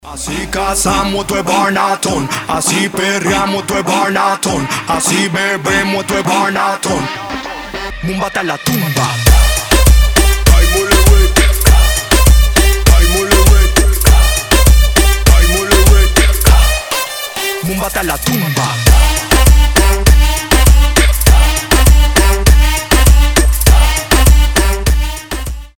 • Качество: 320, Stereo
громкие
зажигательные
заводные
Moombahton
Заводной мумбатон!